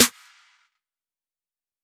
SNARE 26.wav